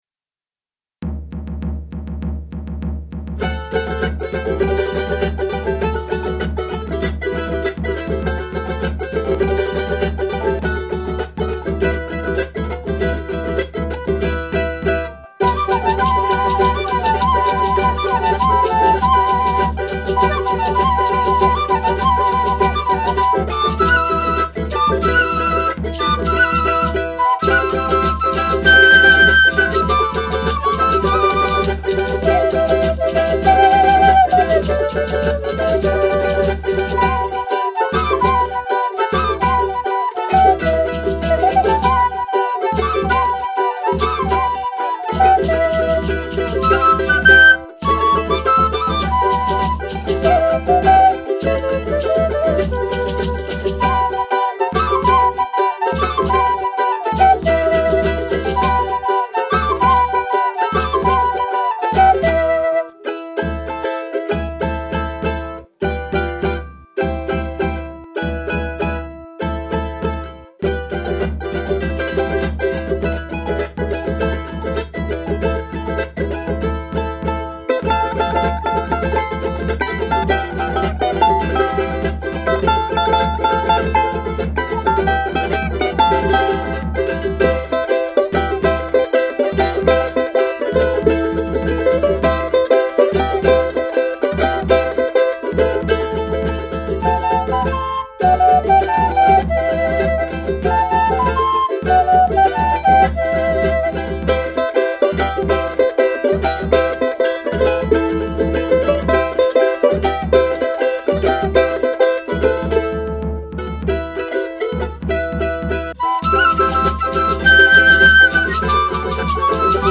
別々に録音した楽器やパソコンで作ったパーカッションをミックスして完成します。
スタジオは鍼灸院の待合室です。